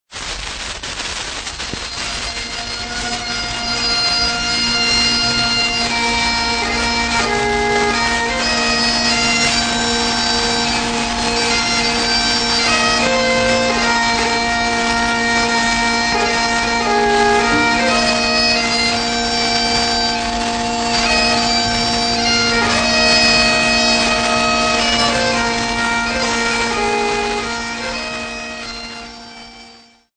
Folk Music
Field recordings
Africa Zimbabwe Bulawayo f-rh
sound recording-musical
Indigenous music